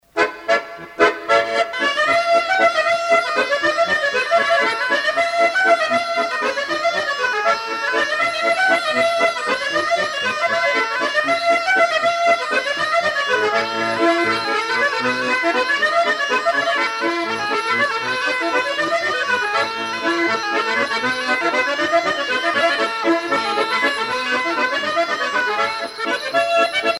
danse : bourree